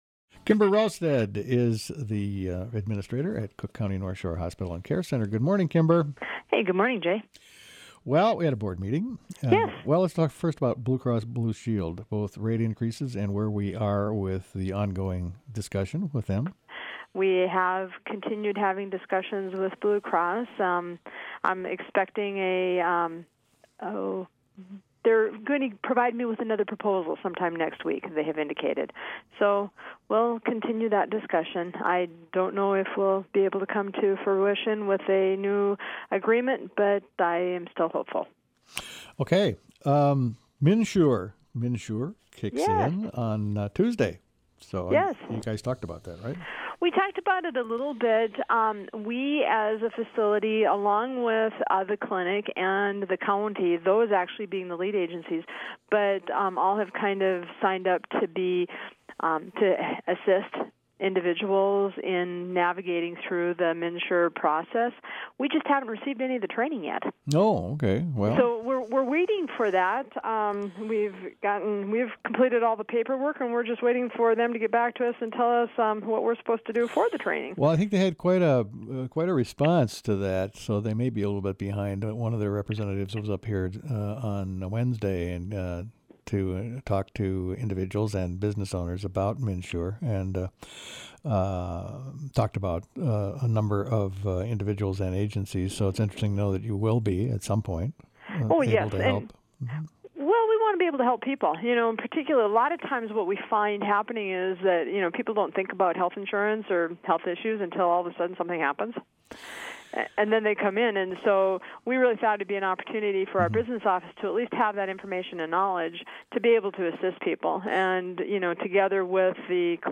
Join the WTIP News Staff for a program packed with news, music and some humor.